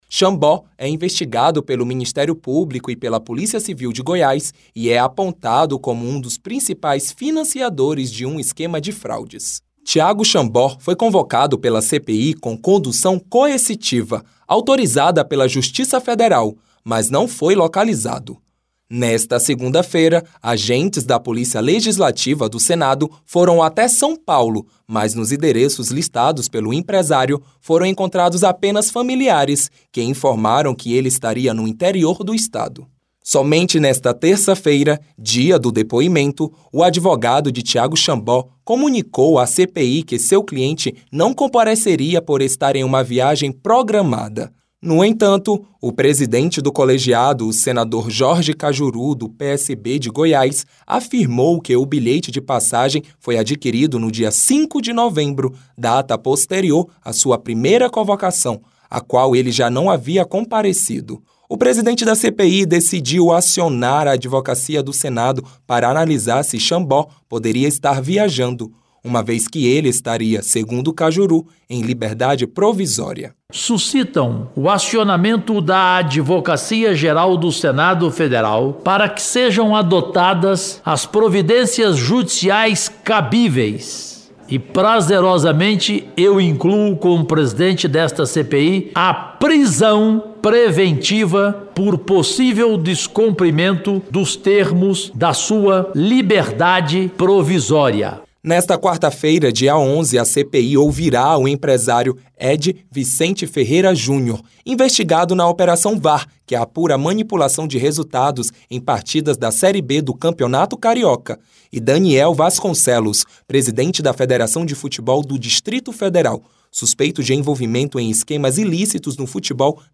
Senador Jorge Kajuru